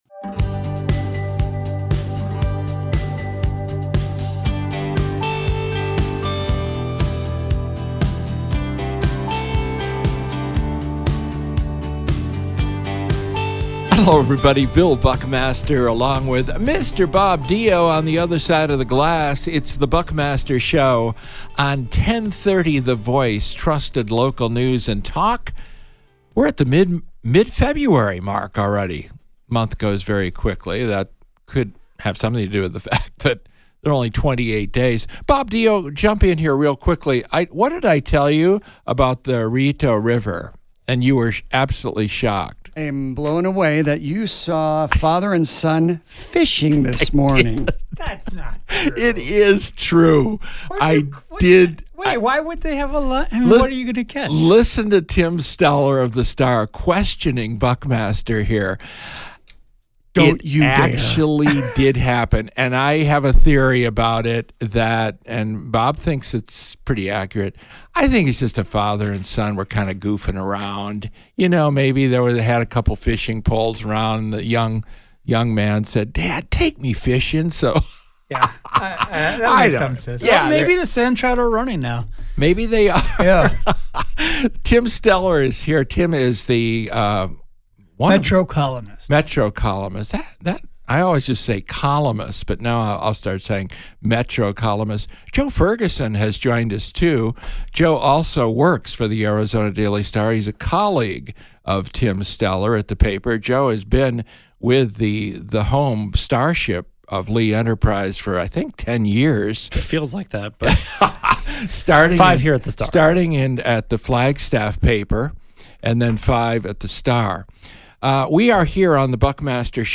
Our Friday Focus interview is with United States Representative Tom O’Halleran (D-CD 1).